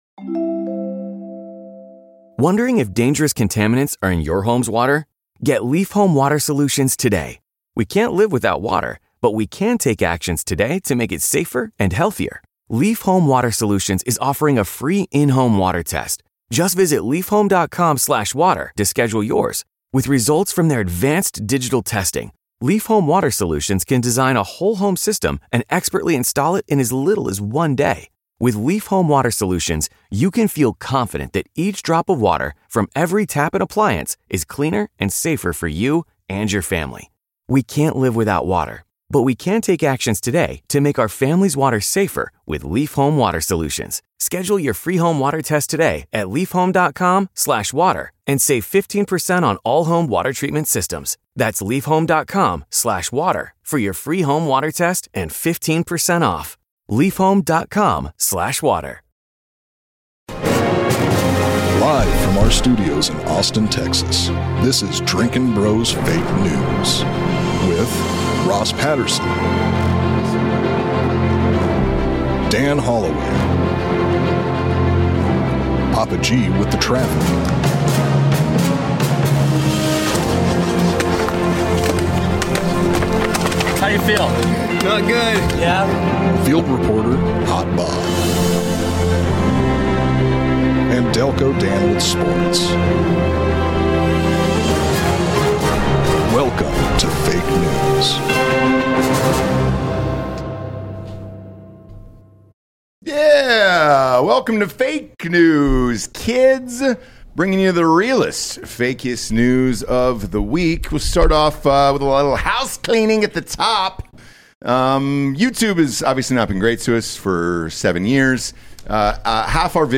Politics, Comedy, News, Sports
Transcript Click on a timestamp to play from that location 0:00.0 Live from our studios in Austin, Texas. 0:04.0 This is Drinking Bros.